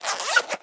Звуки ширинки